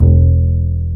Index of /90_sSampleCDs/Roland L-CDX-01/BS _Jazz Bass/BS _Acoustic Bs